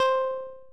noise28.mp3